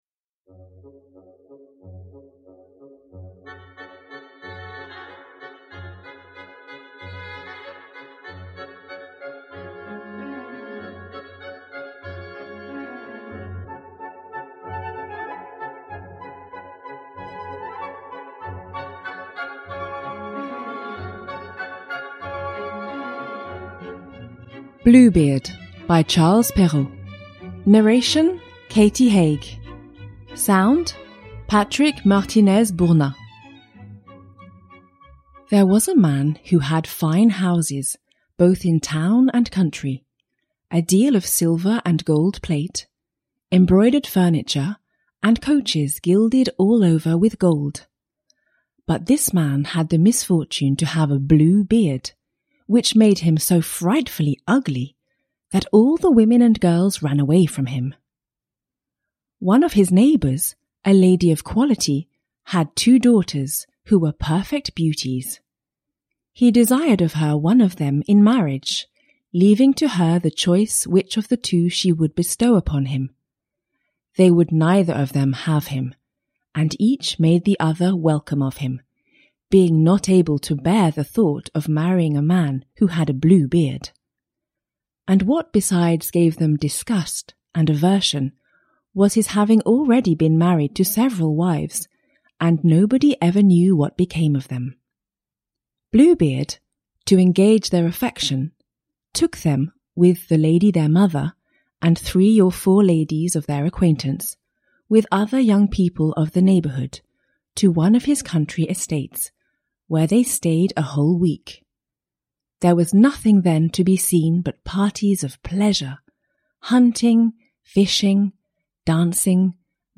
Top 10 Best Fairy Tales – Ljudbok – Laddas ner